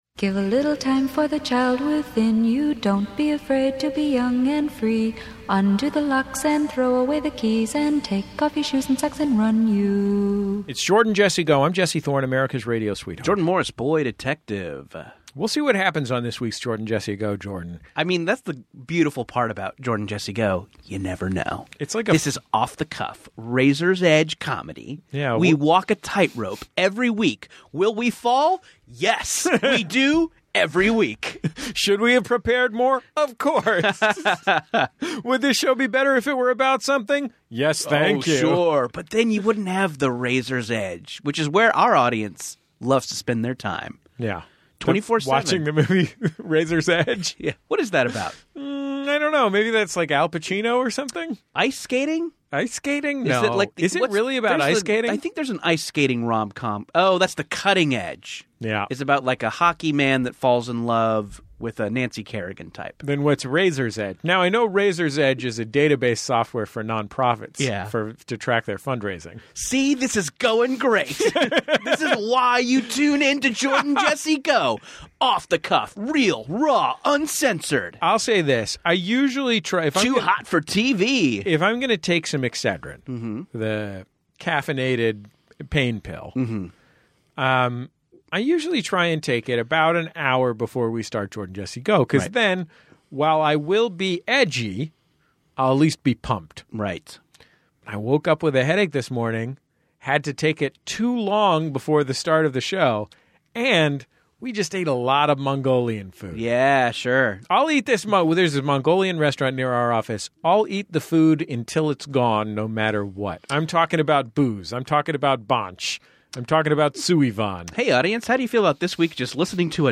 Society & Culture, Comedy, Tv & Film